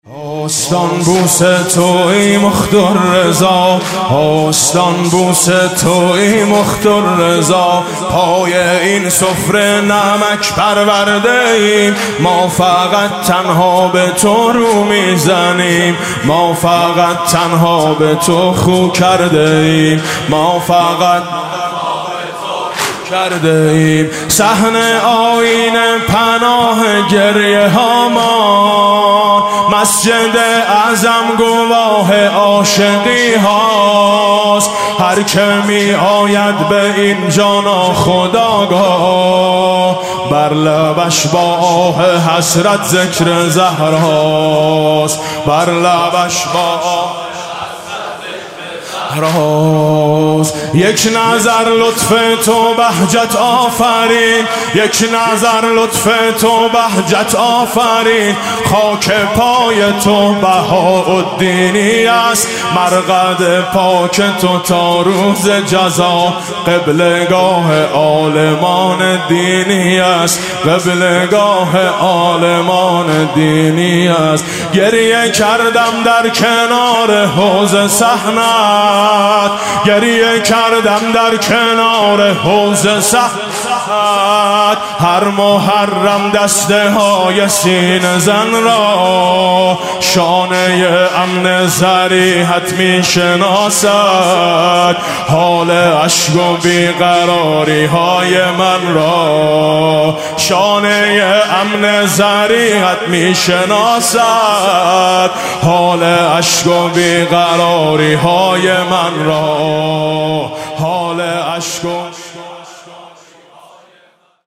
واحد تند